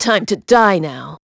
Ripine-Vox_Skill5-02.wav